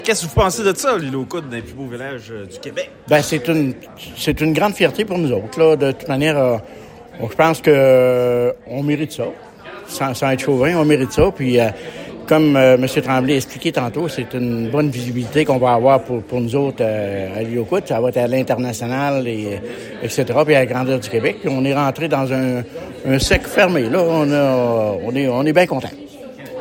Réaction du maire de l’Isle-aux-Coudres, Christyan Dufour.